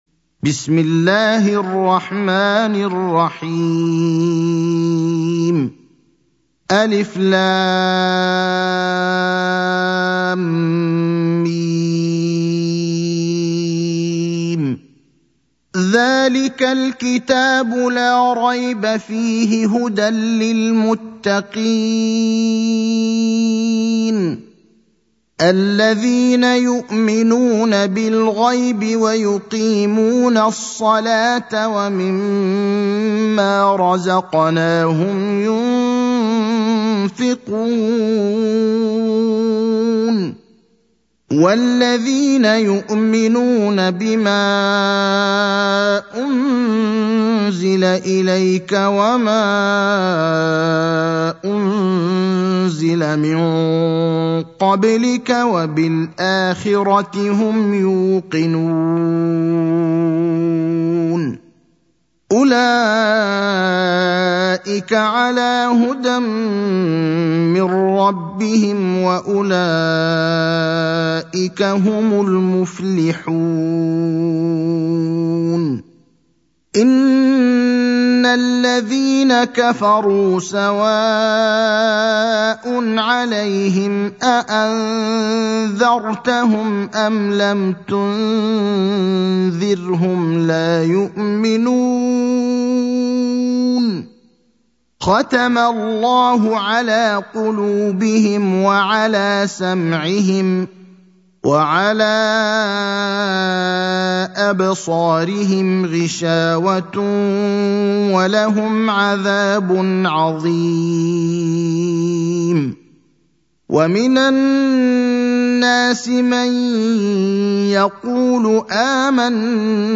تاريخ النشر ١١ ربيع الثاني ١٤٤٧ المكان: المسجد النبوي الشيخ: فضيلة الشيخ إبراهيم الأخضر فضيلة الشيخ إبراهيم الأخضر سورة البقرة